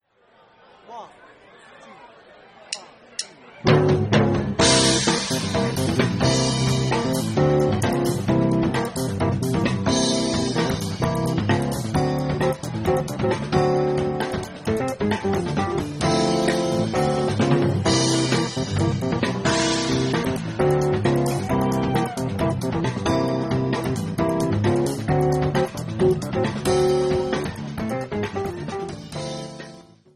live2.mp3